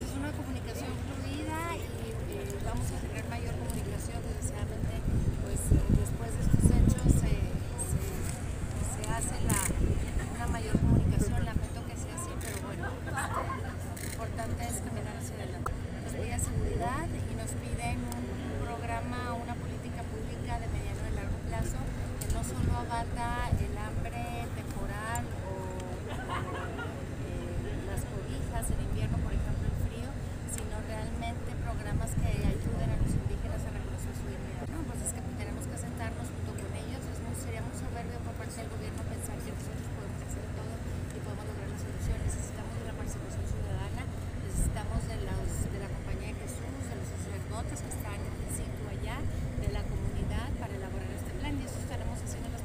Audio. Gobernadora Maru Campos Galván.